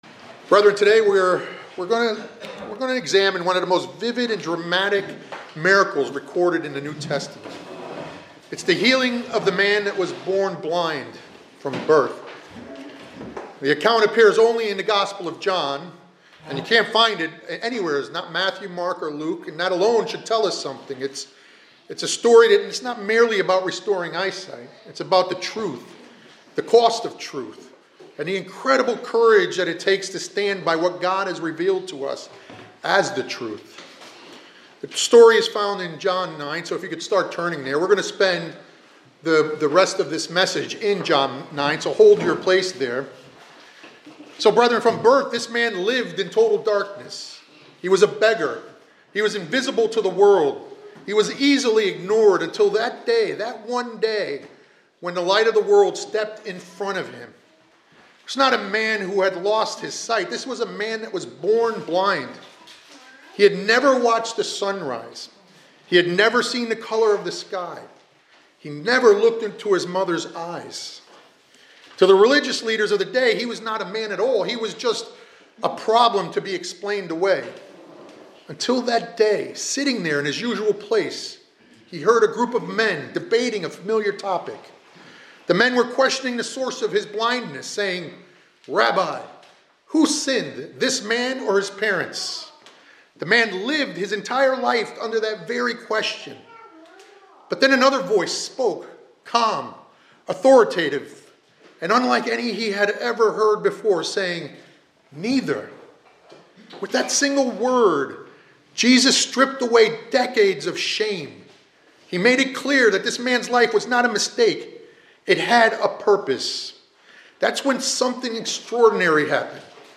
The sermon calls believers to walk as children of light now—enduring trials, obeying God’s word, and standing firm—while looking ahead to God’s Kingdom, when all darkness and blindness will be removed.